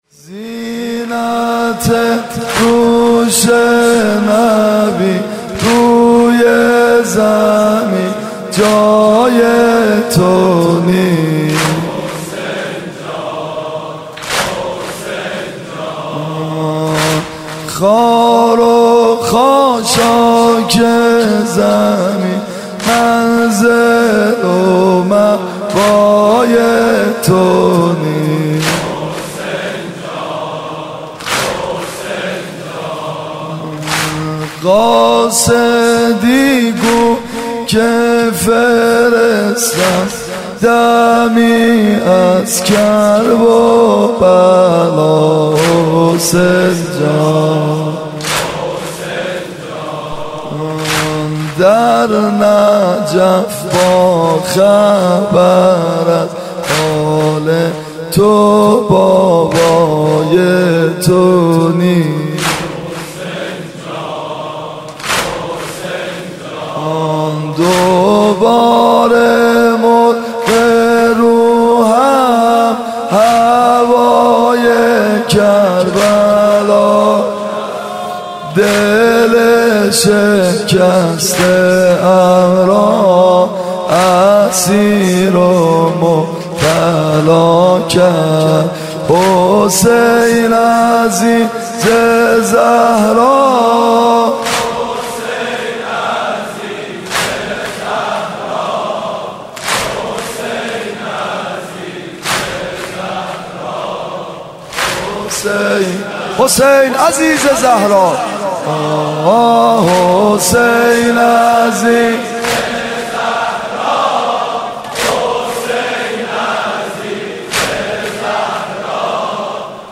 نوحه‌خوانی و مرثیه سرایی